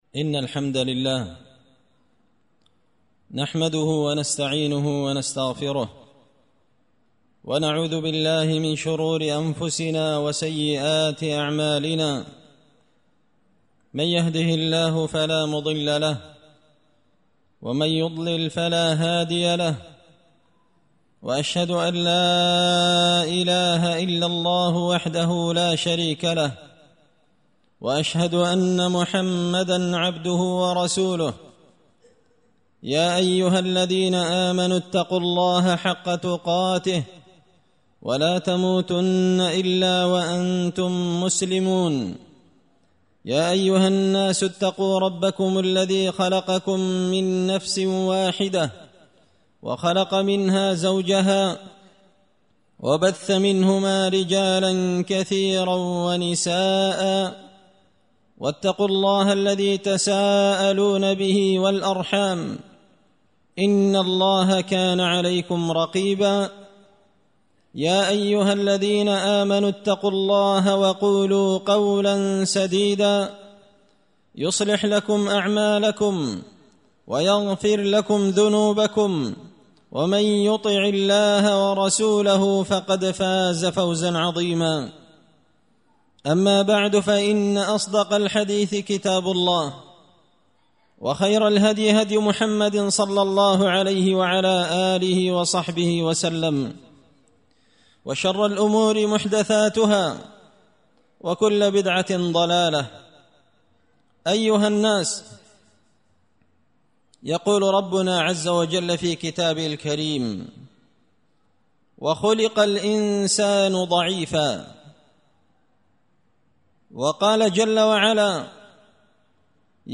خطبة جمعة بعنوان
دار الحديث بمسجد الفرقان ـ قشن ـ المهرة ـ اليمن